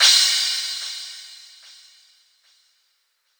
Crashes & Cymbals
Metro Crash.wav